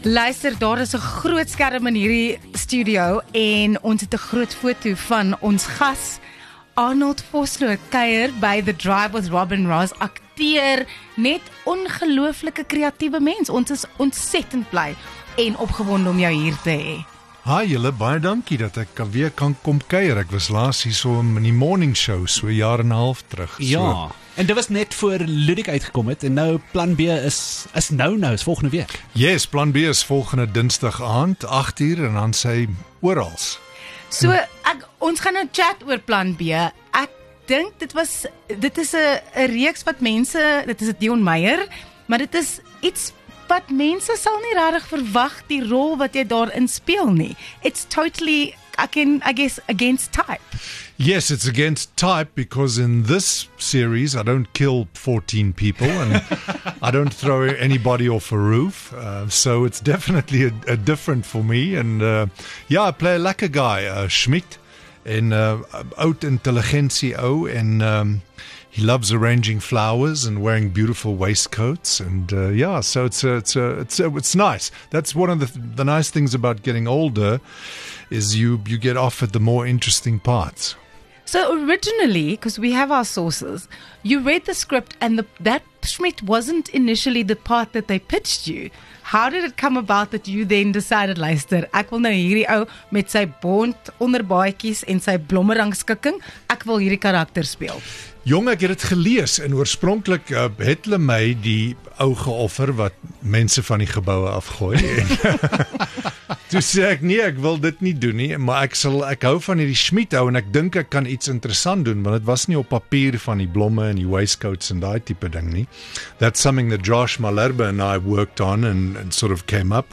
20 Mar Ikoon Arnold Vosloo gesels oor sy nuwe reeks 'Plan B'!